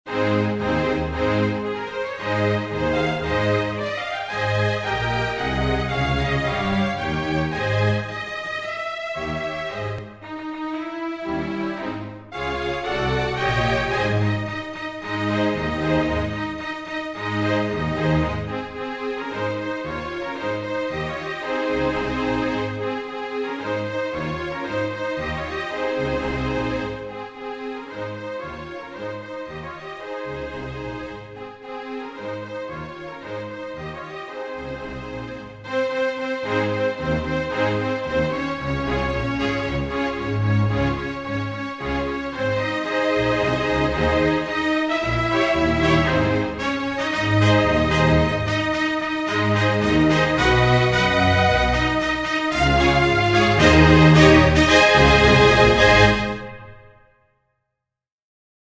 инструментальная версия с небольшими изменениями в звучании